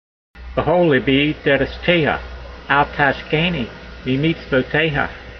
Sound (Psalm 119:10) Transliteration: be hol lee b ee derash tee ha , al - tash 'gay nee mee meetsv o tay ha Vocabulary Guide: I have sought you with all my heart : do not let me go astray from your commandment s . Translation: I have sought you with all my heart: do not let me go astray from your commandments.
v10_voice.mp3